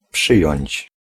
Ääntäminen
IPA : /əkˈsɛpt/